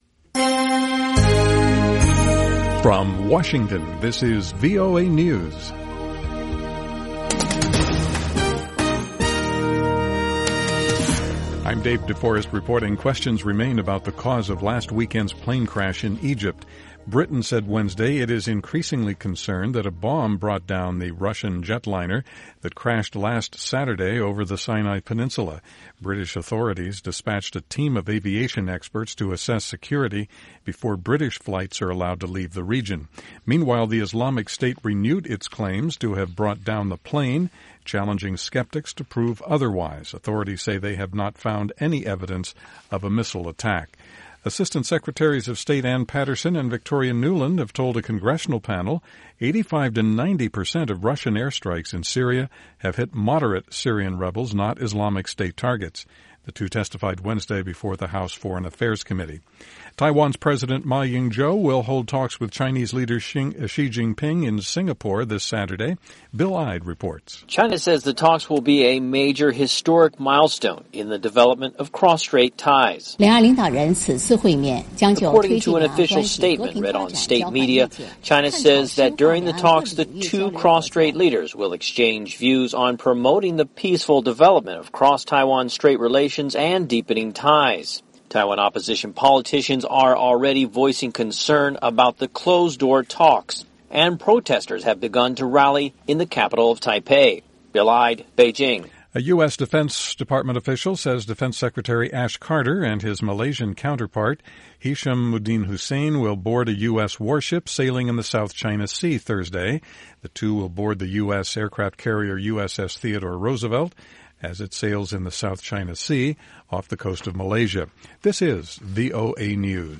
VOA English Newscast 2100 UTC November 4, 2015